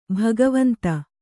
♪ bhagavanta